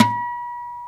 NYLON B 4 HM.wav